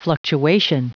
Prononciation du mot fluctuation en anglais (fichier audio)
Prononciation du mot : fluctuation